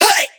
VR_vox_hit_hey2.wav